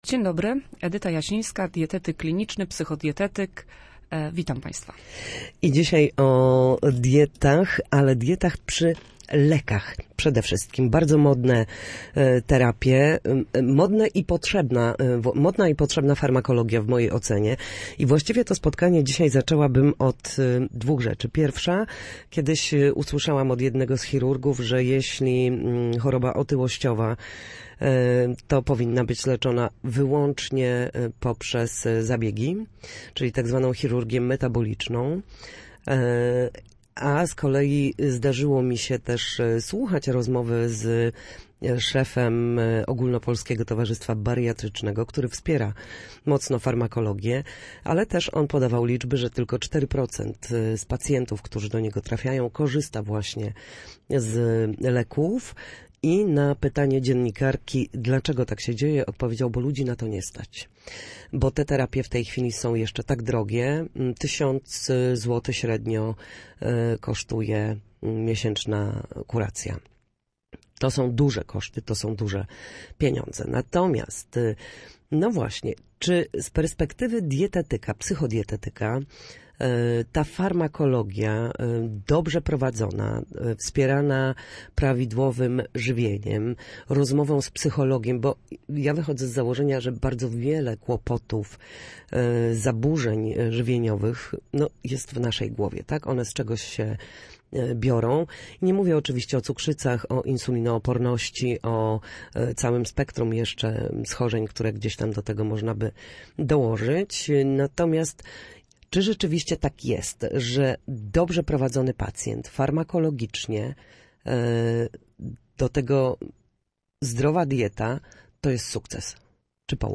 W każdą środę, w popołudniowym Studiu Słupsk Radia Gdańsk, w audycji „Na zdrowie” rozmawiamy o tym, jak wrócić do formy po chorobach i urazach. Nasi goście – lekarze i fizjoterapeuci – odpowiadają na pytania słuchaczy, doradzają, jak radzić sobie z najczęstszymi dolegliwościami, i podpowiadają, co m